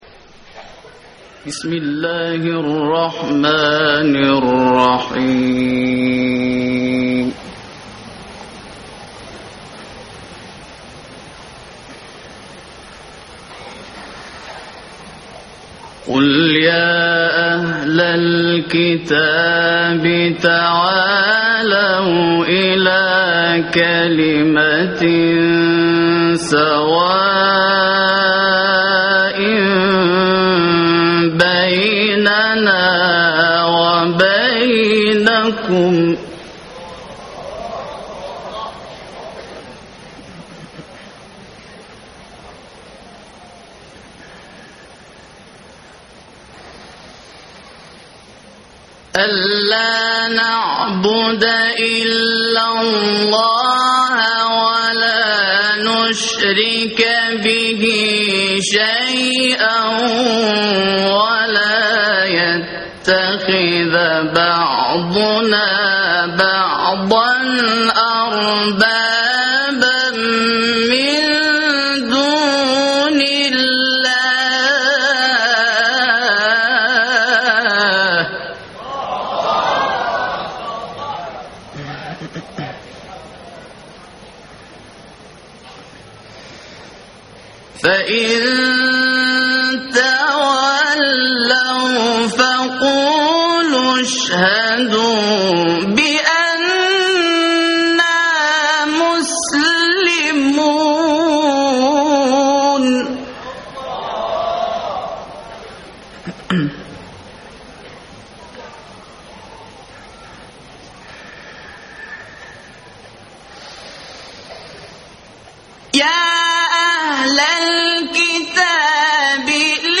دانلود قرائت سوره آل عمران در مسابقات بین المللی قرآن مالزی